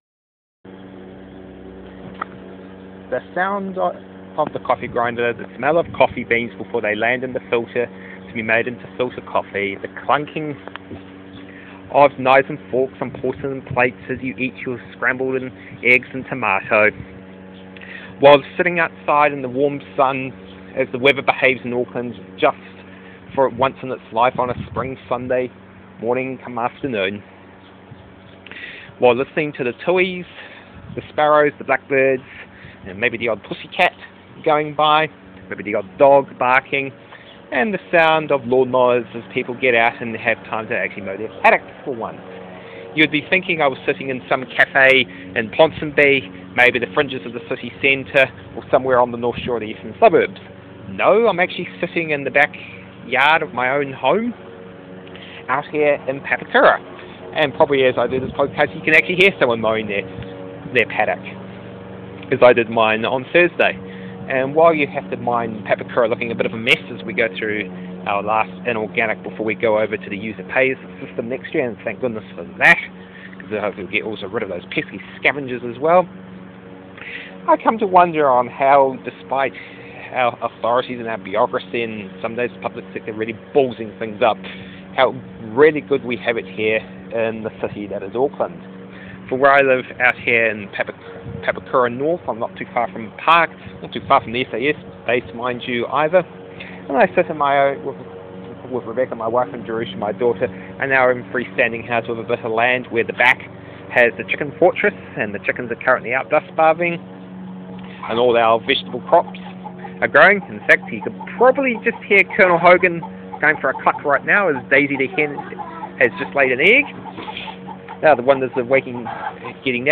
We have it pretty sweet in The Big City of New Zealand The Sunday BBQ Podcast on Location – my backyard